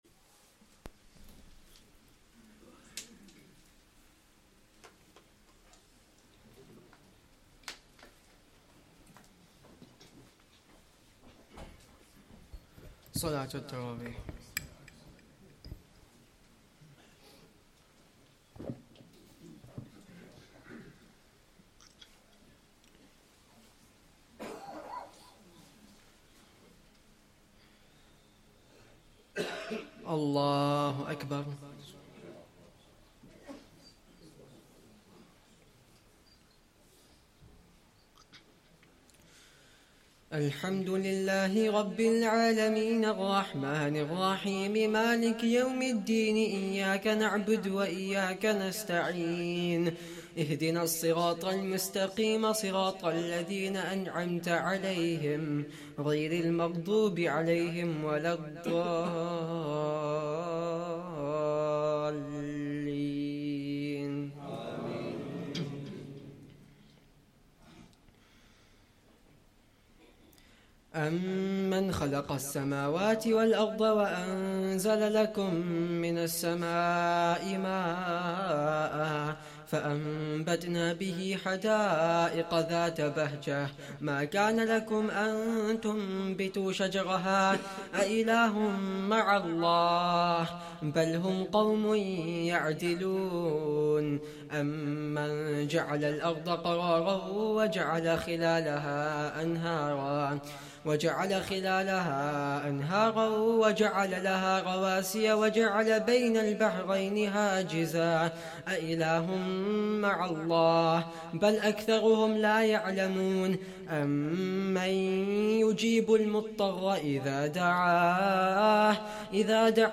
2nd Tarawih prayer - 18th Ramadan 2024